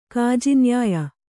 ♪ kājinyāya